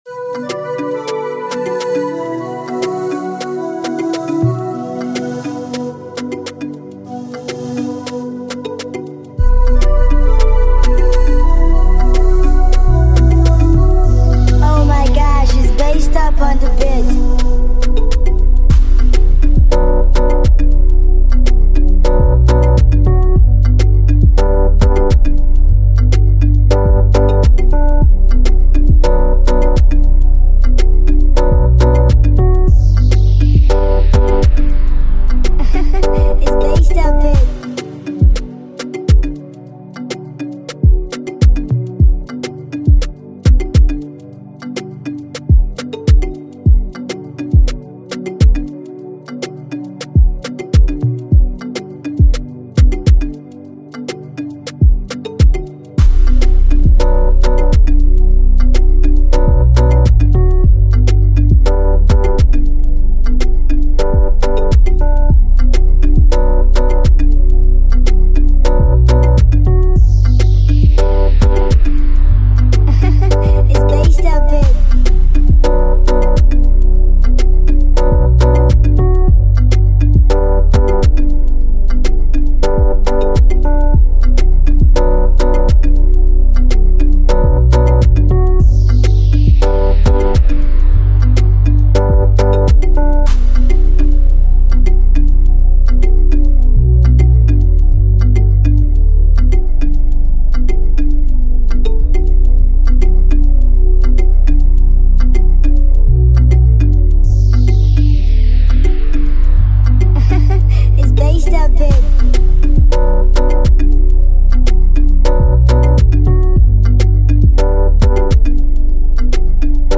Download instrumental mp3 below…